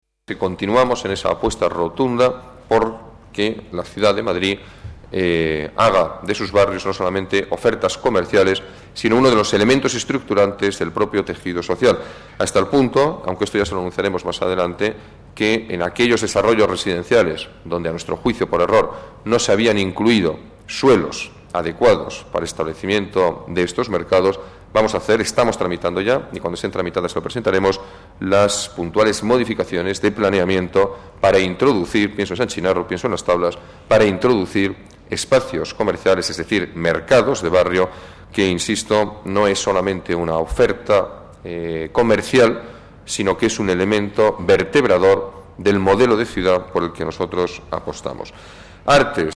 Nueva ventana:Declaraciones Ruiz-Gallardón: apuesta por la modernización mercados de barrio